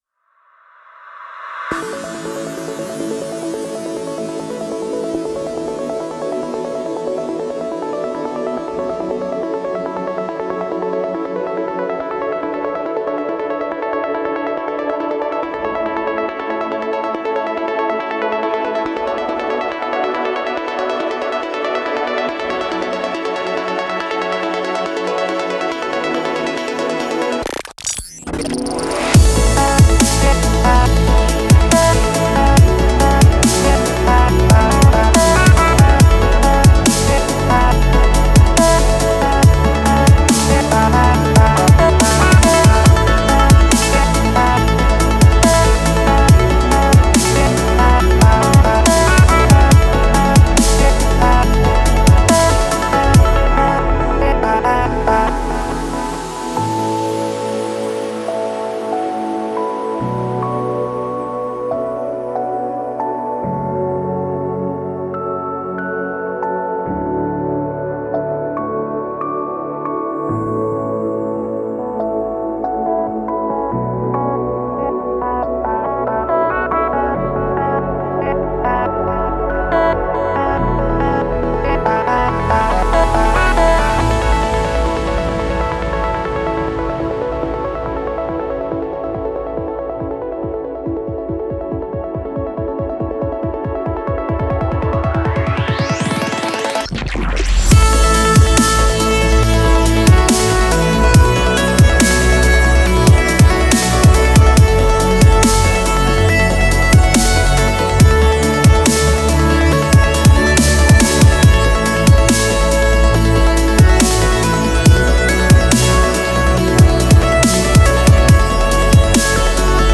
это трек в жанре электроника с элементами синти-попа
при участии вокалистки